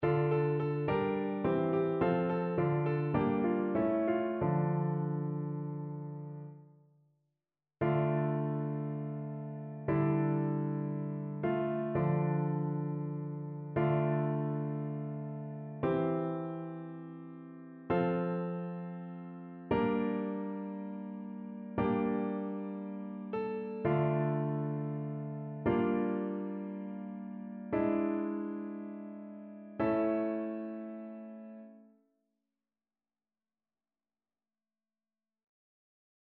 ChœurSopranoAltoTénorBasse